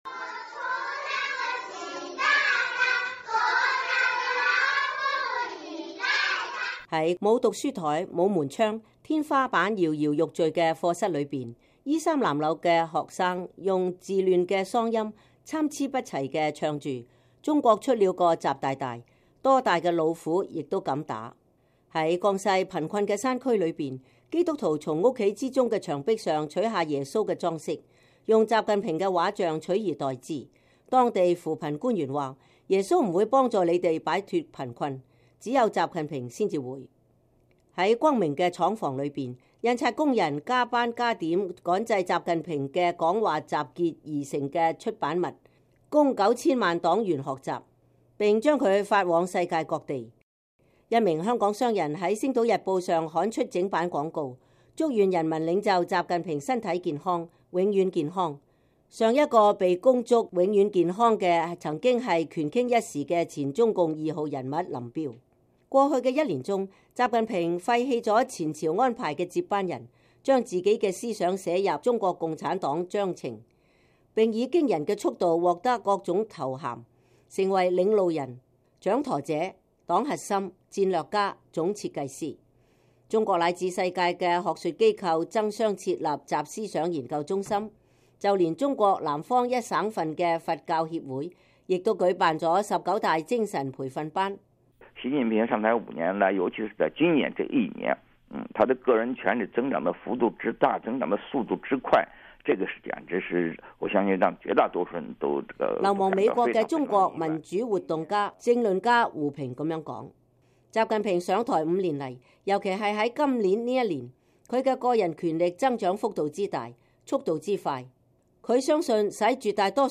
在沒有課桌、沒有門窗、天花板搖搖欲墜的教室裡，衣衫襤褸的孩子用稚嫩的嗓音參差不齊地唱著：“中國出了個習大大，多大的老虎也敢打”。